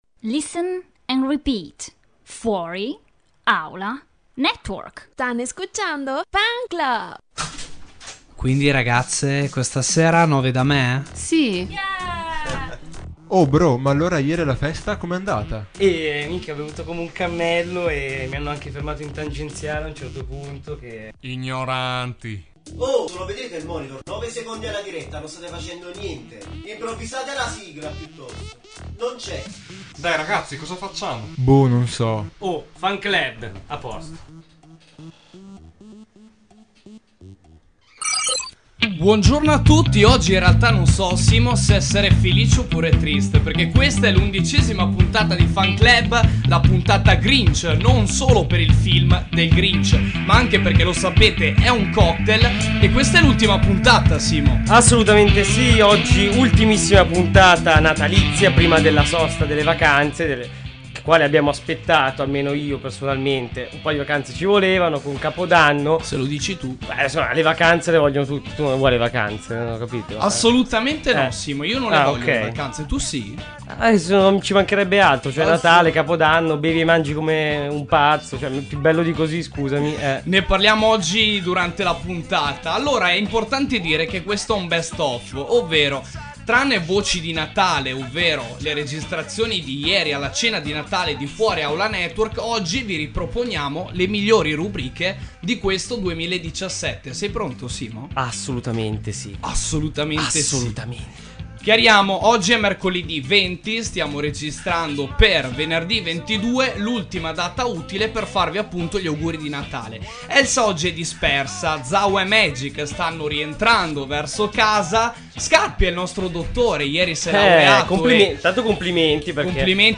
Le compromettenti voci degli speaker di FuoriAulaNetwork direttamente dalla cena di Natale